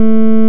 Que l'on peut aussi �couter ... (son "triangle" 44 Ko)
DES G �N� RATEURS ET DES OSCILLOSCOPES AVEC VIRTUALWAVES La sortie d'un g�n�rateur (oscillateur) est envoy�e sur un haut-parleur.
triangle.wav